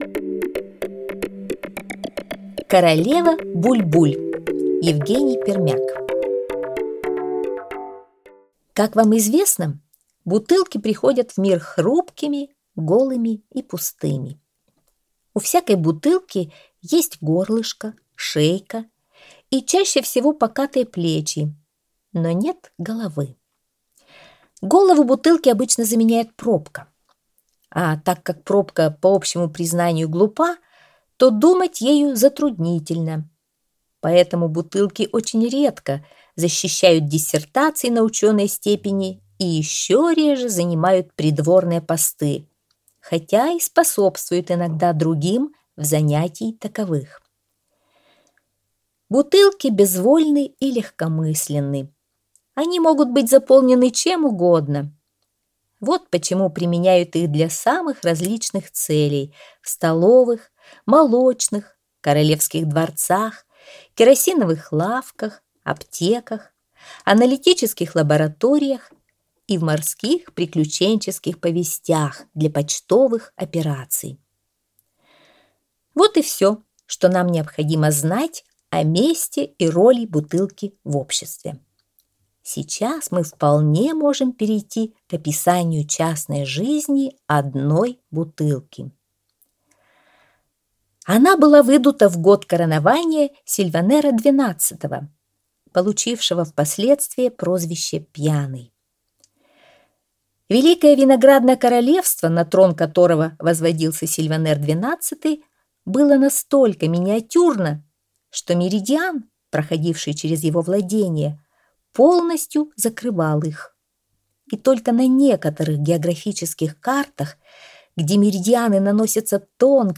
Королева Буль-Буль - аудиосказка Пермяка - слушать онлайн